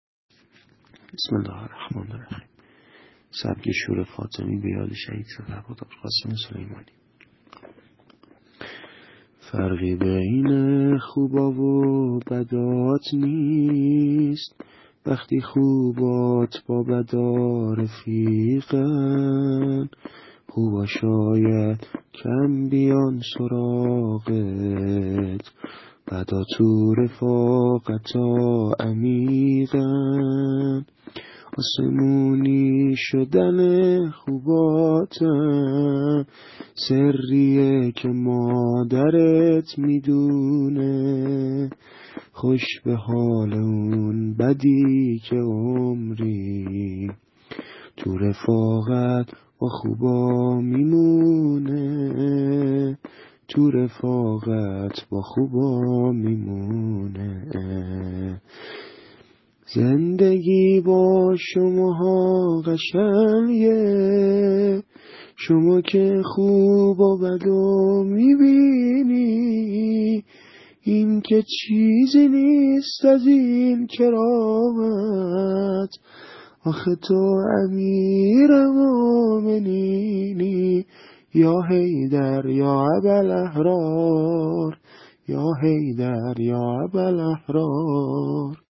متن شعر سبک شور ایام فاطمیه98 به یاد شهید سردار حاج قاسم سلیمانی -(فرقی بین خوبا و بدات نیست)